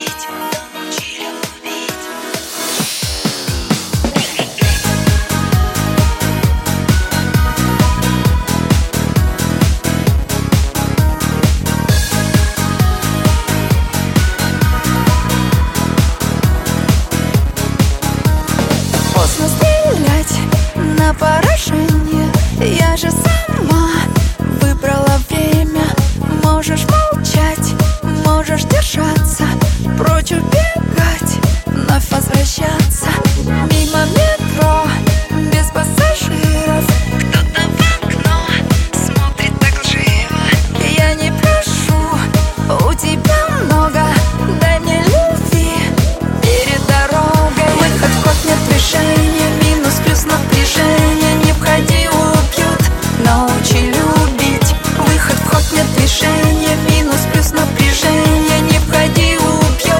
Pop
качественной танцевальной поп-музыки европейского уровня